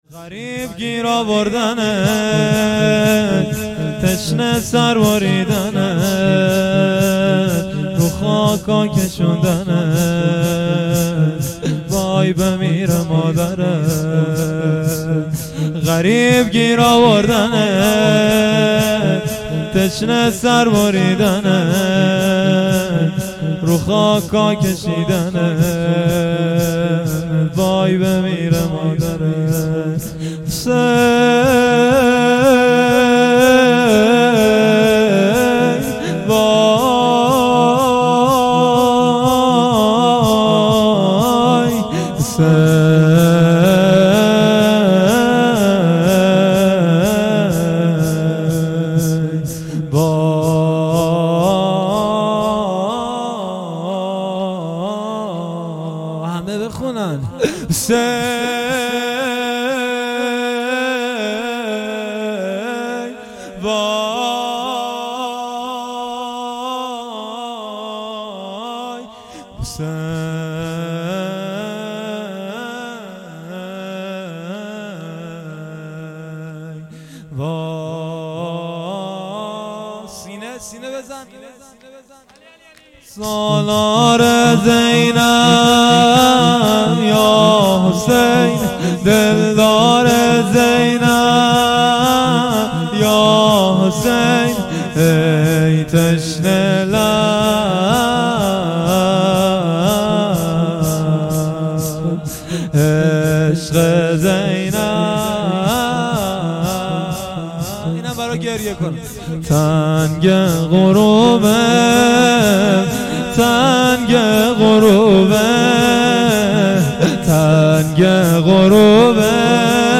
مراسم عزاداری دهه اول محرم الحرام 1399 - گلزار شهدای هرمزآباد
گلزار شهدای هرمزآباد